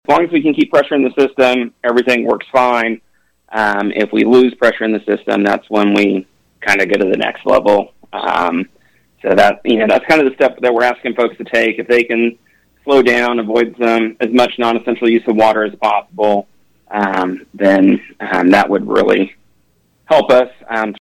Here’s City Manager Trey Cocking: